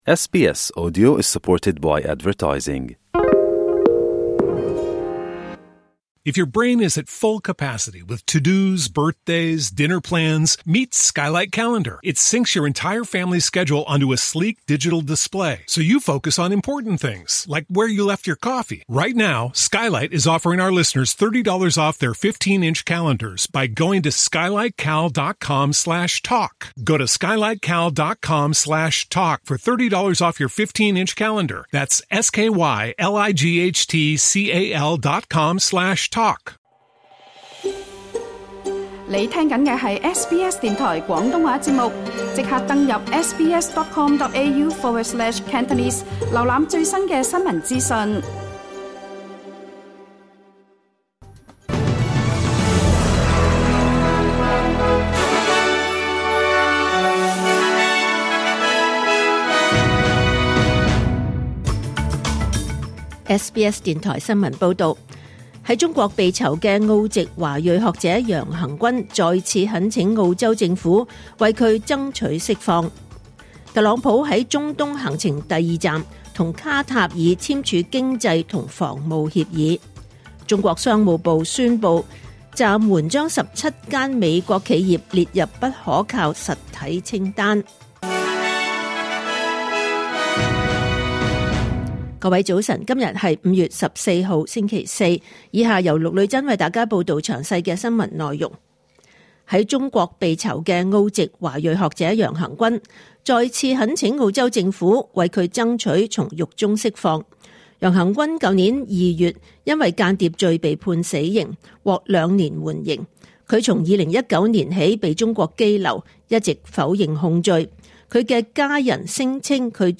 2025年5月15日，SBS 廣東話節目九點半新聞報道。